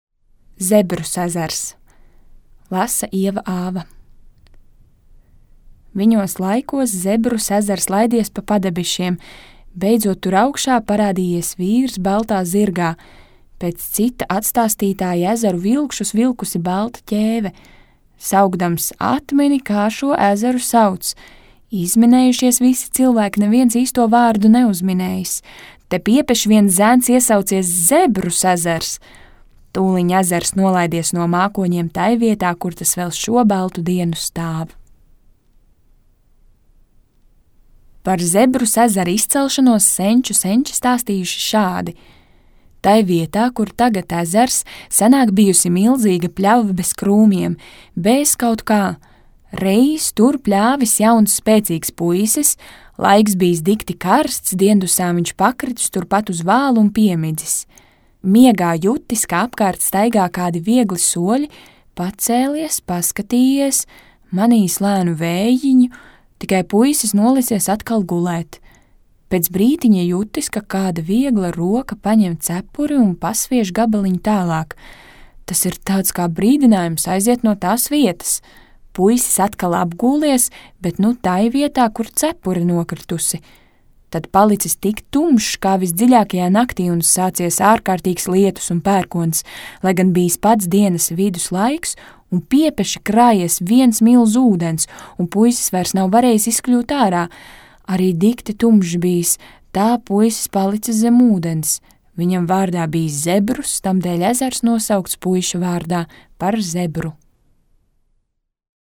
Teikas
Teicējs: